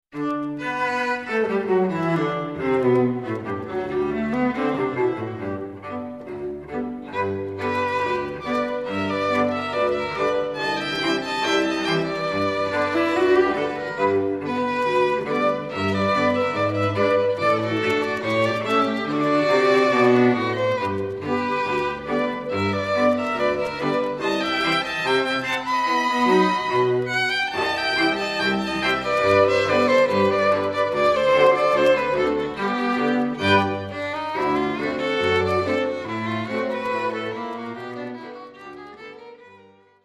Click the blue titles below to hear Cotswold Ensemble string quartet players performing.